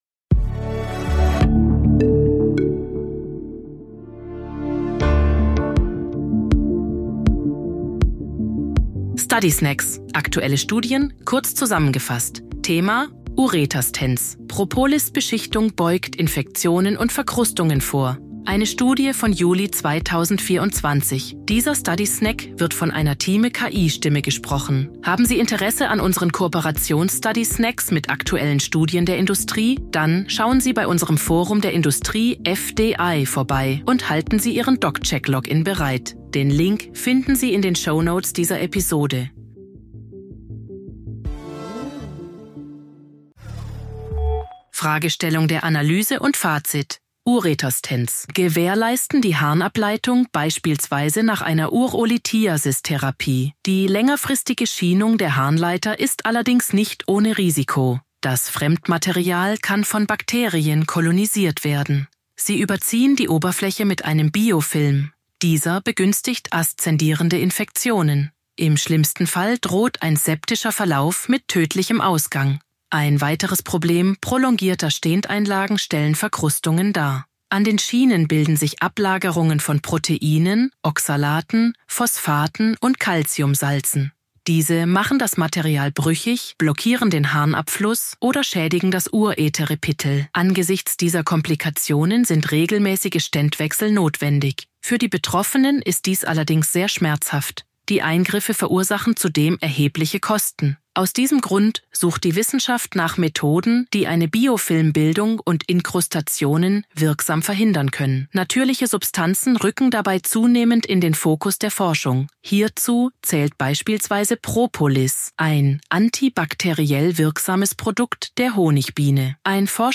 Intelligenz (KI) oder maschineller Übersetzungstechnologie